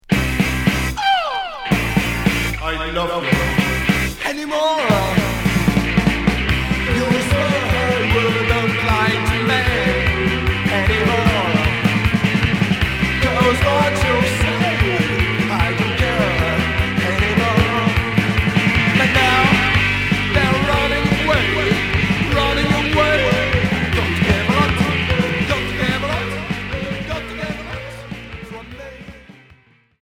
Psychobilly